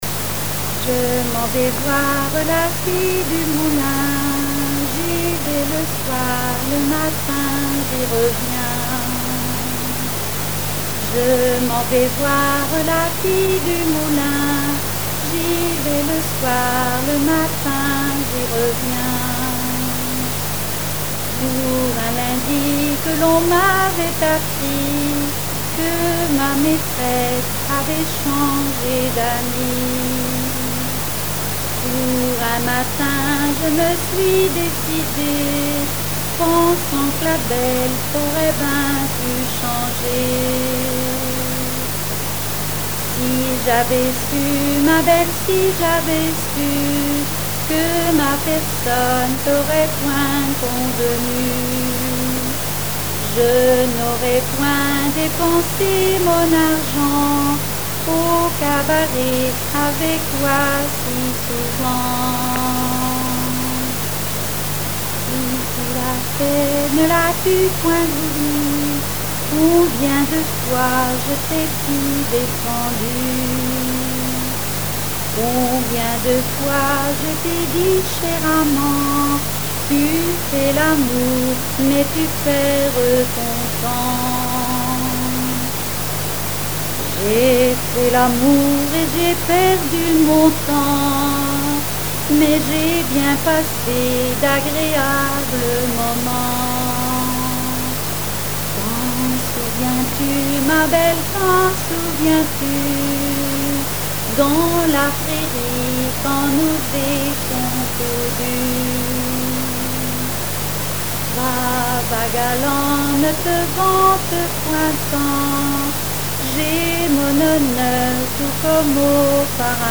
Genre dialogue
répertoire de chansons populaire et traditionnelles
Pièce musicale inédite